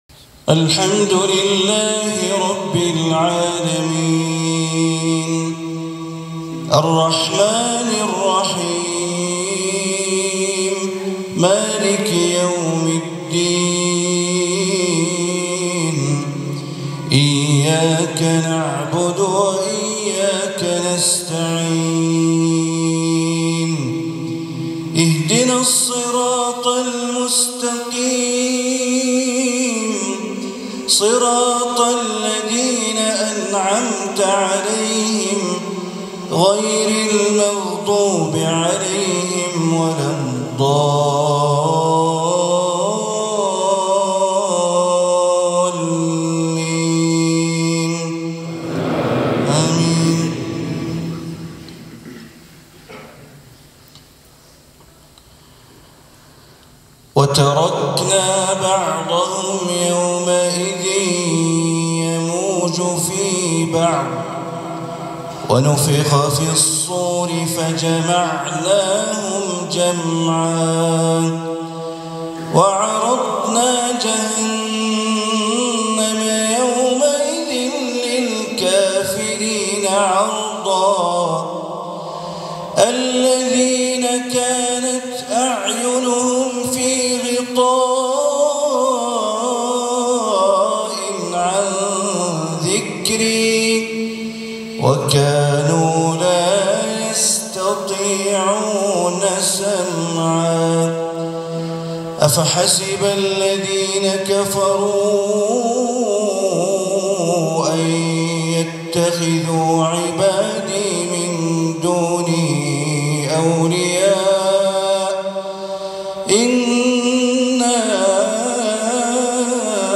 صلاة المغرب 11 ذو القعدة 1446هـ من جامع ماتي في عاصمة كوسوفو بريشتينا، لفضيلة الشيخ د بندربليلة > زيارة الشيخ بندر بليلة الى جمهورية كوسوفو > المزيد - تلاوات بندر بليلة